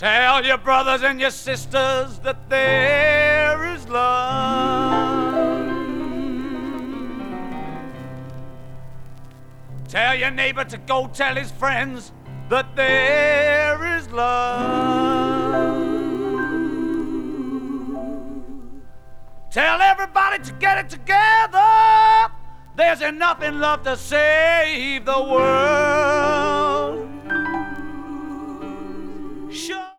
Mono promo single version